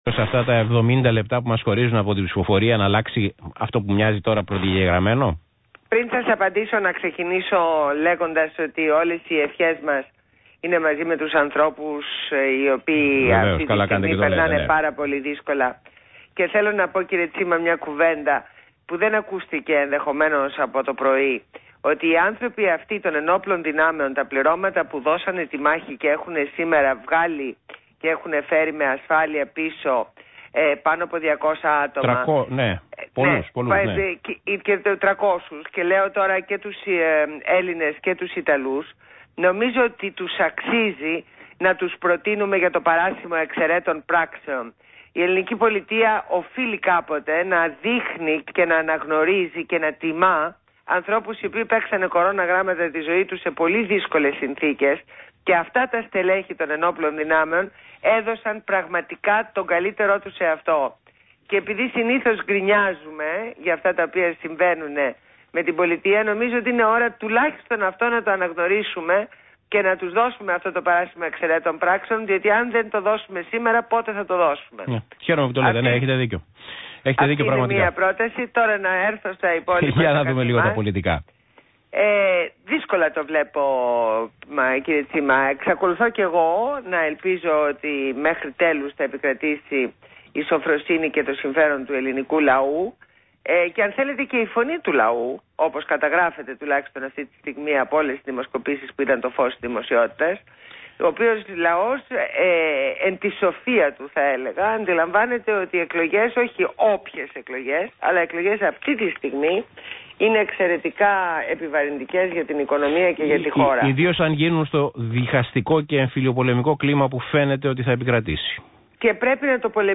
Συνέντευξη στο ραδιόφωνο του ΣΚΑΪ